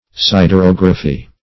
siderography - definition of siderography - synonyms, pronunciation, spelling from Free Dictionary
Siderography \Sid`er*og"ra*phy\, n. [Gr. ??? iron + -graphy.]